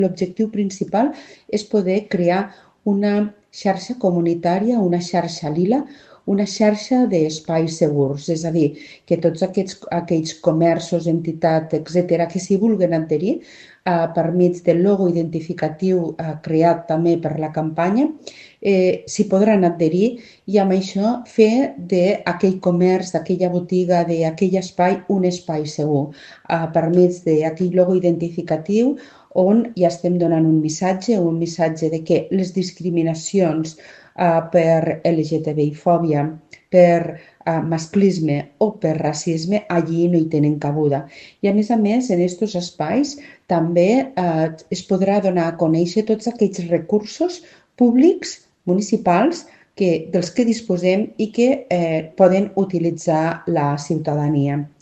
Així ho explica Mar Lleixà, regidora d’Igualtat i Feminismes…